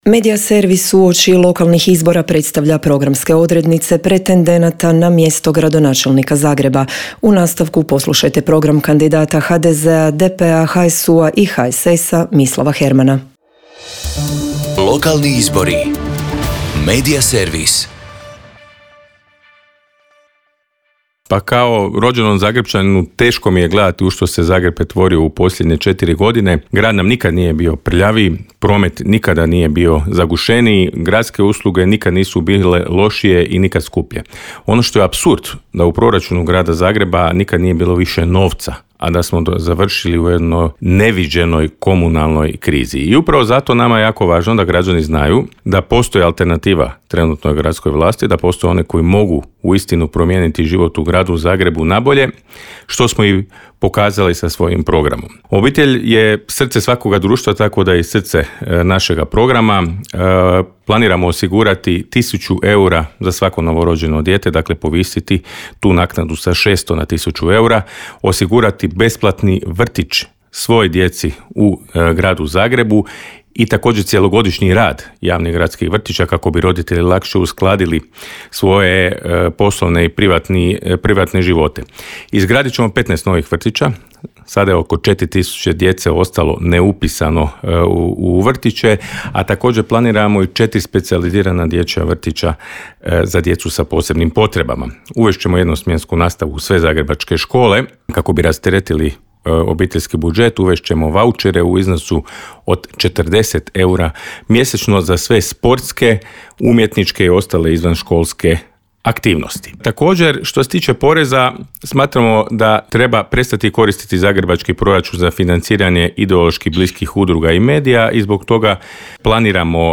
ZAGREB - Uoči lokalnih izbora kandidati za gradonačelnicu/gradonačelnika predstavljaju na Media servisu svoje programe u trajanju od pet minuta. Kandidat za gradonačelnika Zagreba ispred HDZ-a, DP-a, HSU-a i HSS-a Mislav Herman predstavio je građanima svoj program koji prenosimo u nastavku.